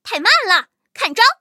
M18地狱猫开火语音1.OGG